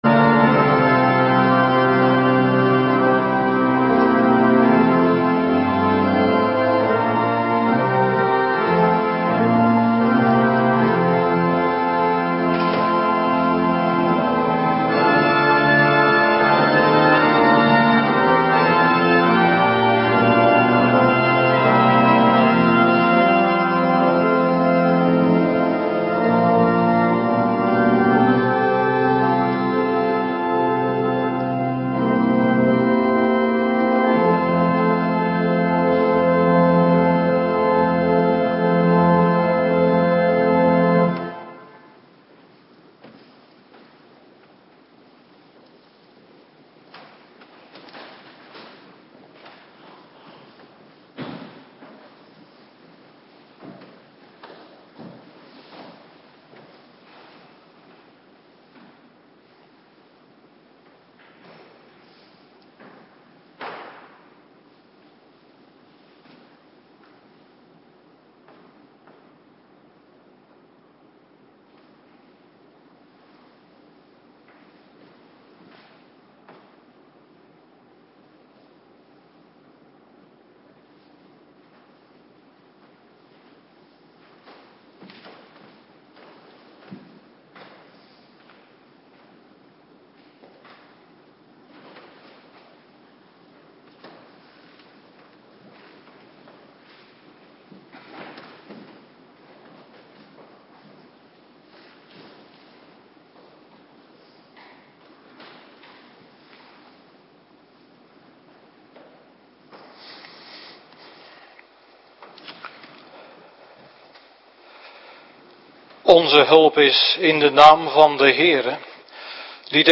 Avonddienst Dankdag - Cluster 2
Locatie: Hervormde Gemeente Waarder